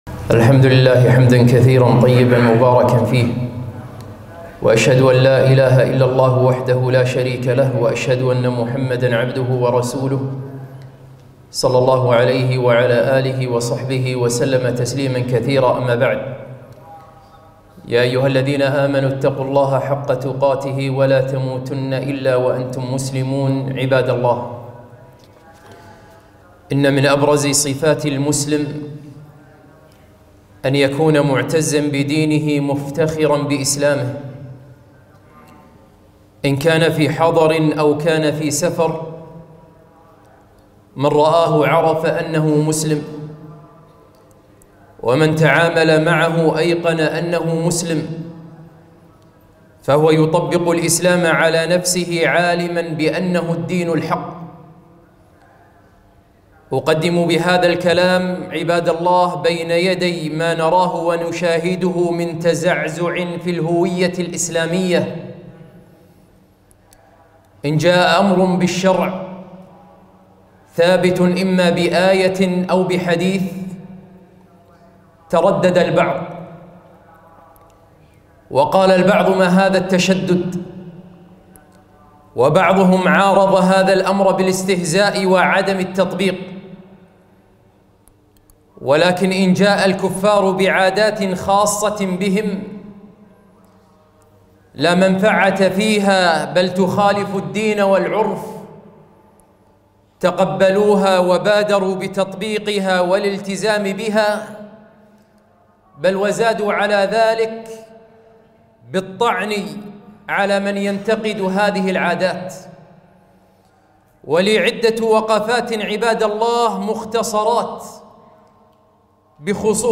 خطبة - لماذا لا نحتفل بالكريسميس ورأس السنة ؟